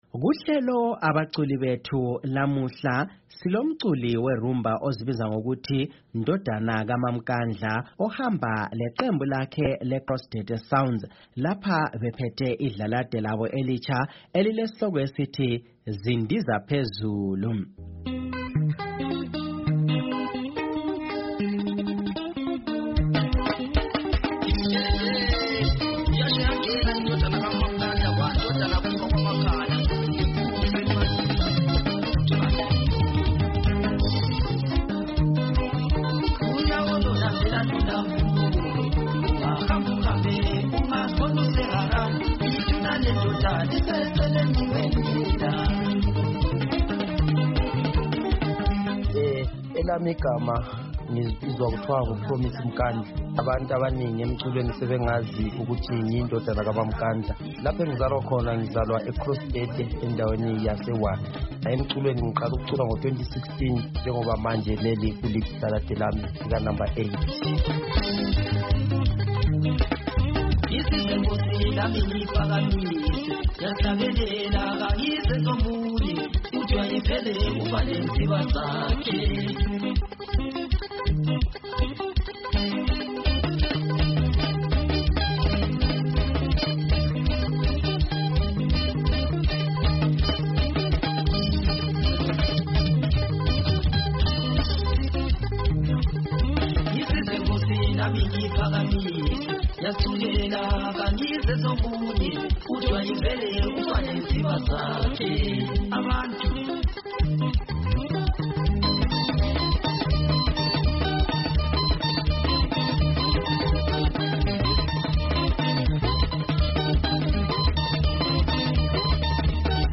Kuhlelo ‘Abaculi Bethu’ liviki silomculi we-rhumba